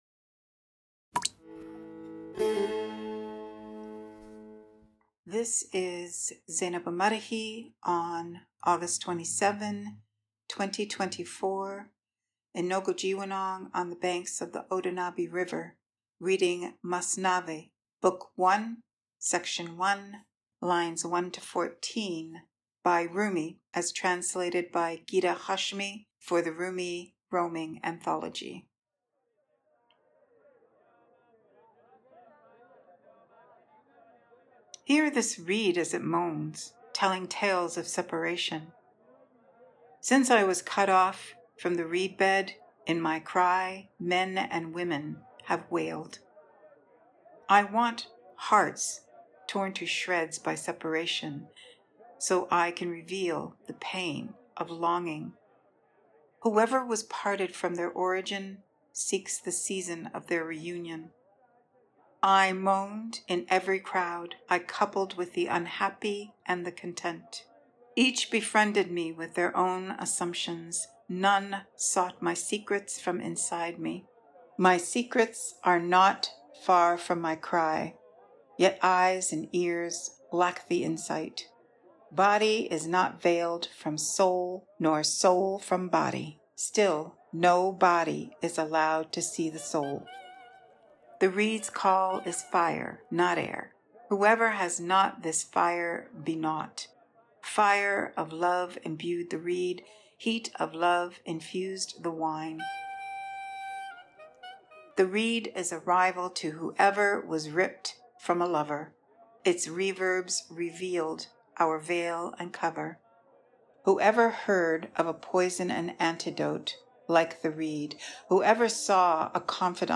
Rumi, Masnavi, Translation, Rumi Roaming, Poetry